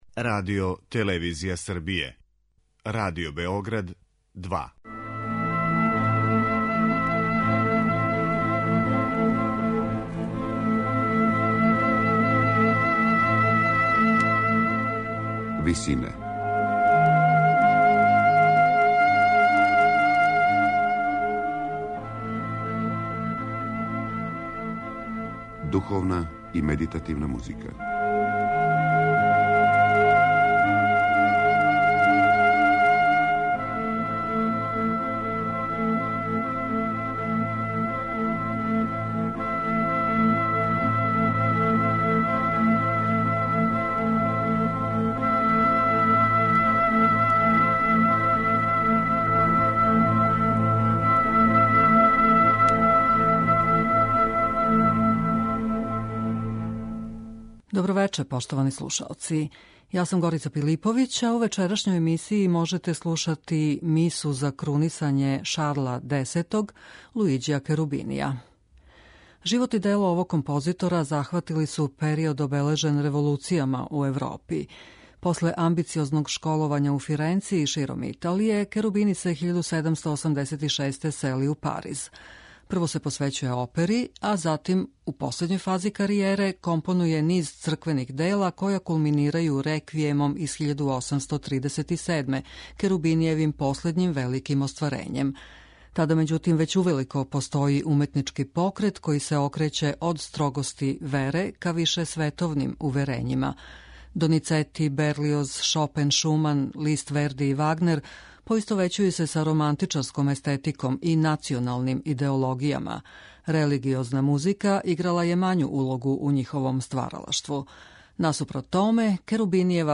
Црквена музика Луиђија Керубинија